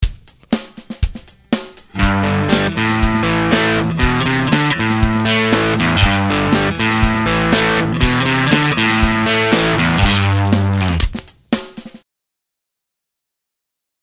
Arpeggio Riff 1 | Download